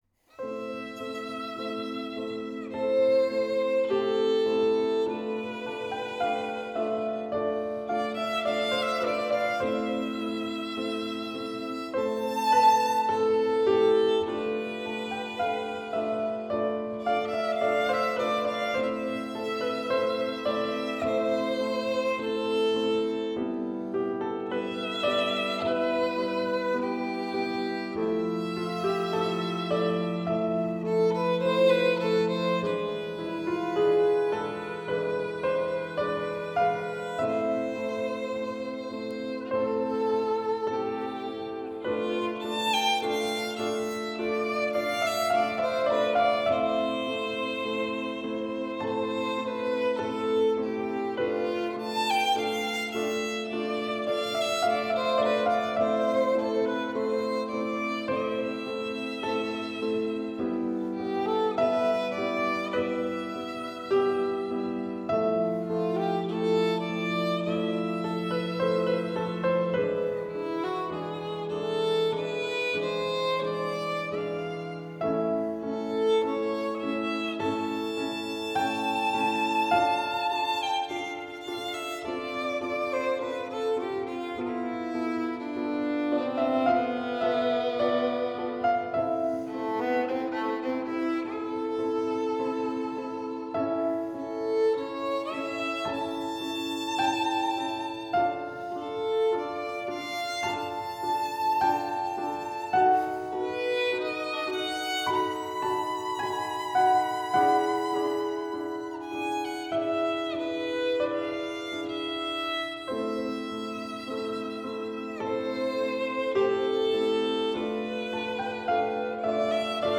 Eine sch�ne Ballade f�r etwas fortgeschrittene Spieler, die erste Kenntnisse der 3. Lage und einen sch�nen Ton verlangt. Die Klavierstimme ist auch von Kindern/Jugendlichen gut spielbar.